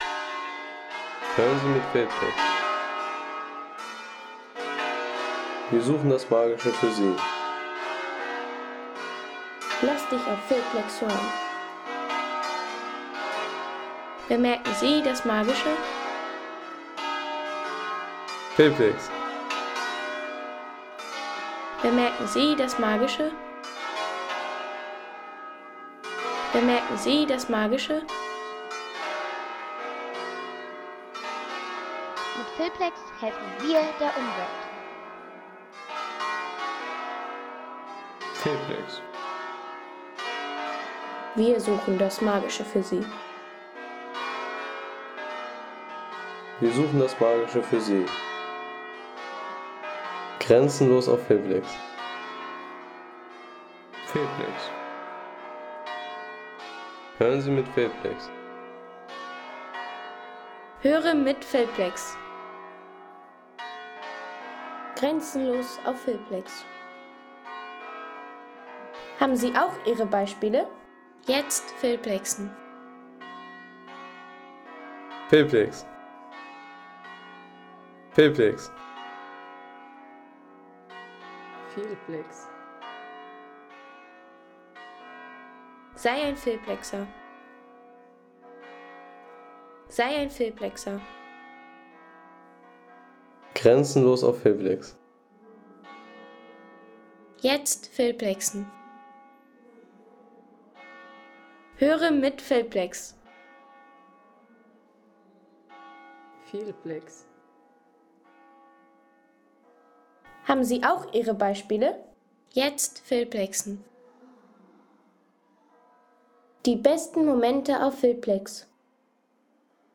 Basilika San Vittore in Verbania
Kirchen - Landschaft